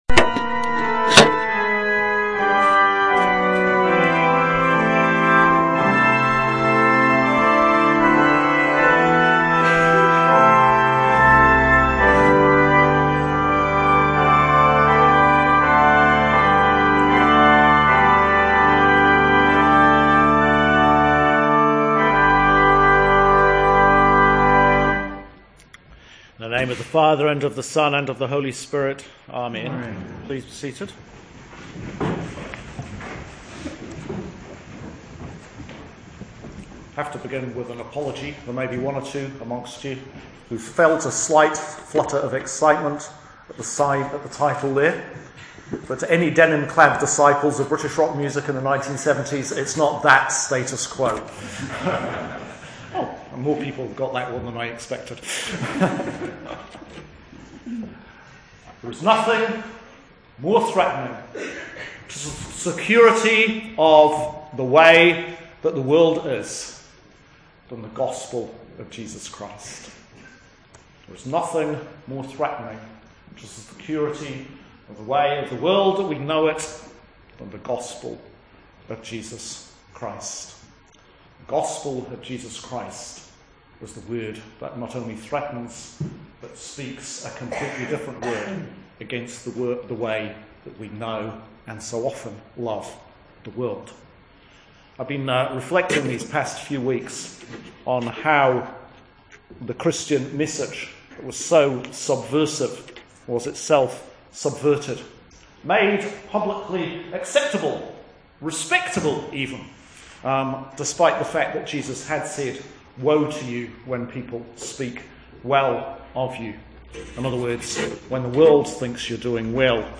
Sermon for 30th Sunday in Ordinary Time, Year B October 25th 2015